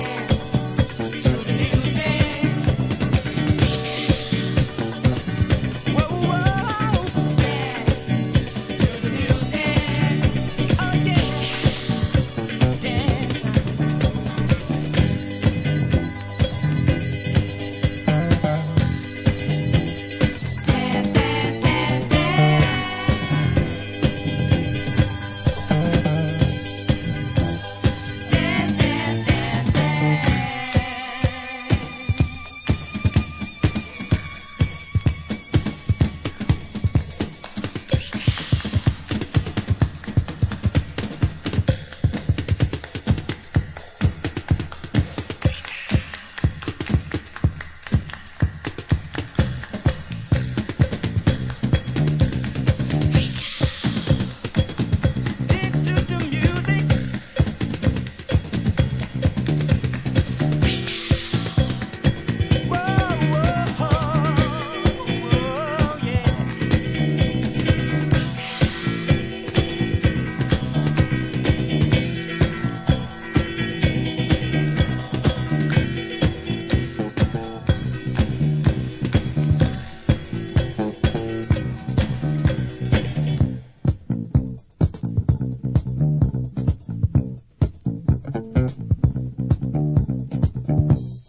Another rare disco classic